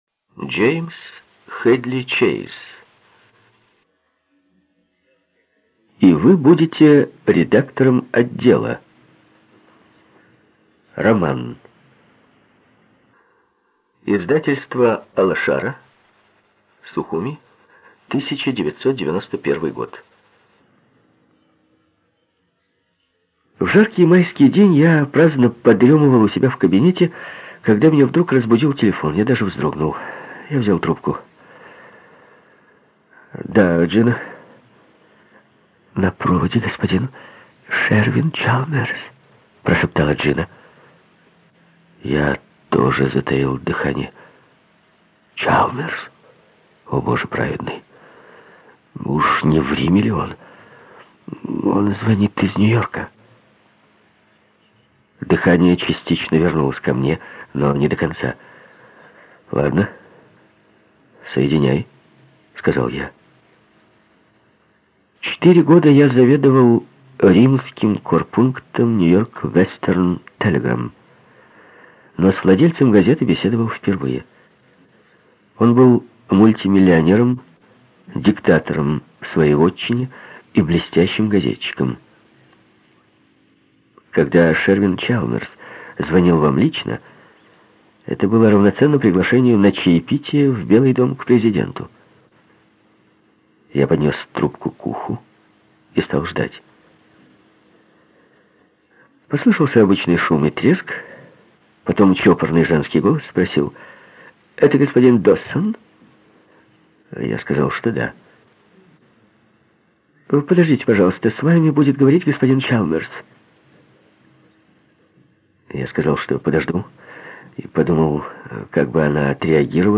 ЖанрДетективы и триллеры
Студия звукозаписиРеспубликанский дом звукозаписи и печати УТОС